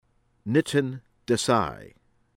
DEORA, MURLI MOOR-lee   DEER-ah